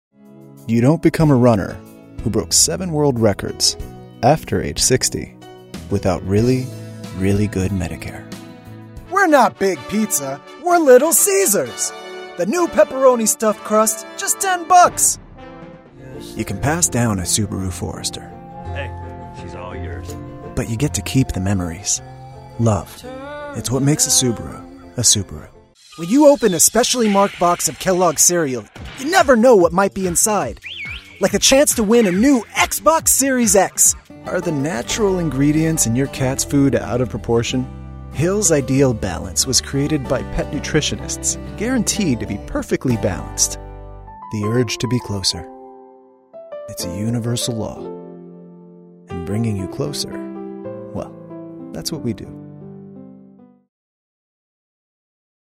Voiceover Reels
Commercial Demo